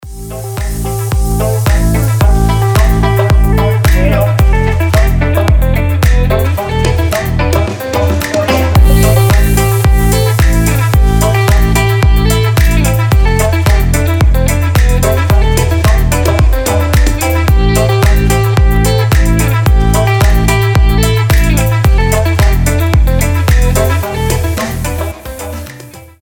deep house
красивая мелодия
Классный дипчик